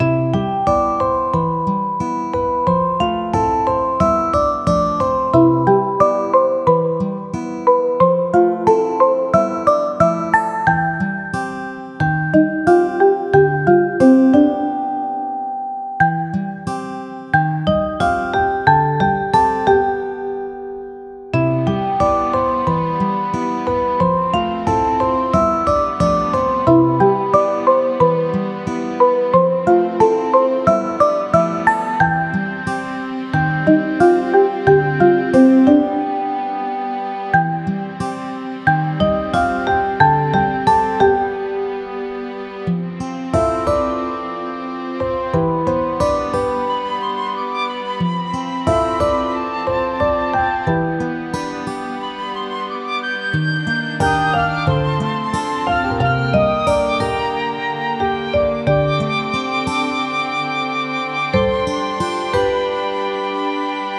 ゆったりした楽曲
【イメージ】会話、おだやか など